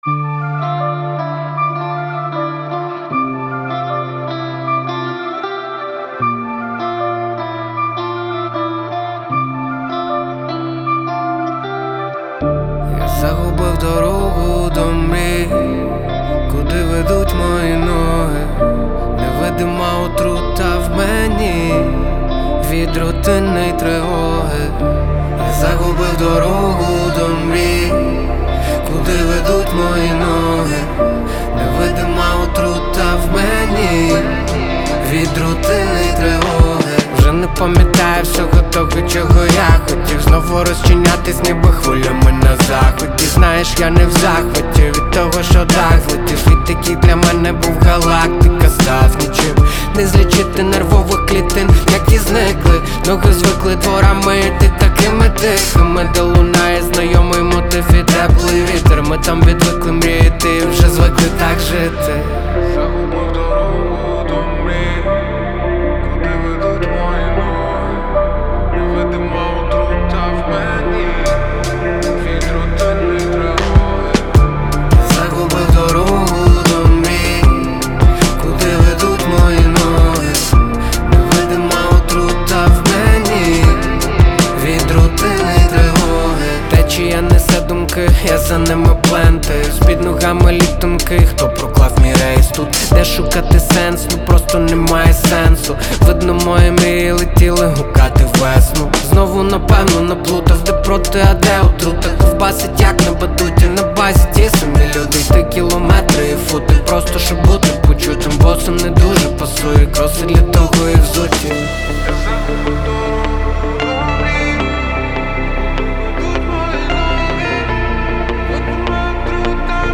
• Жанр: Hip-Hop